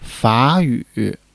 fa3--yu3.mp3